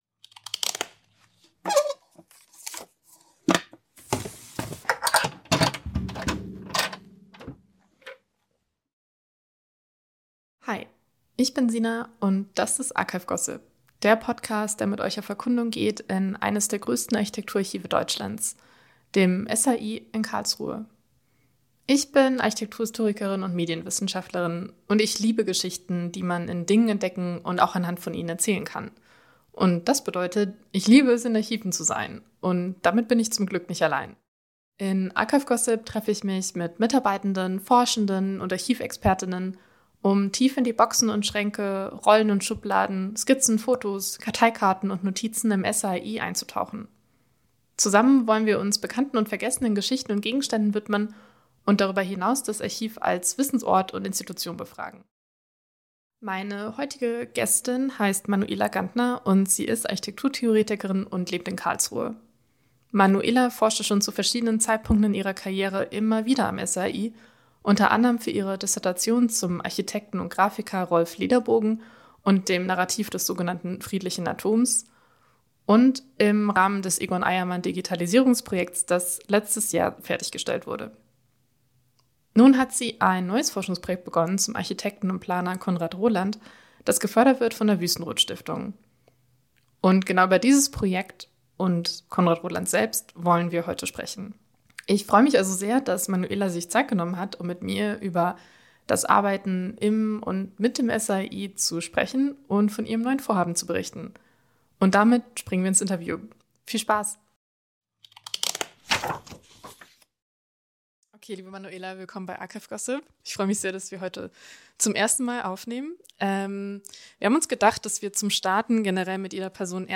Podcast-News